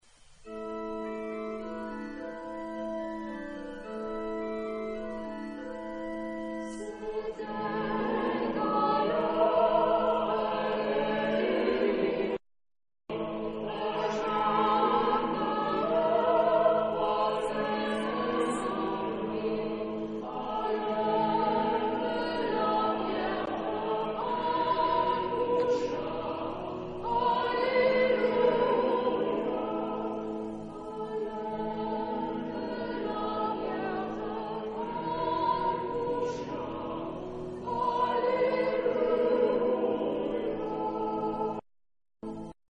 ... Noël francais du 16ème siècle ...
Genre-Style-Forme : noël
Type de choeur : SSATB  (5 voix mixtes )
Instruments : Orgue (1)
Tonalité : la mineur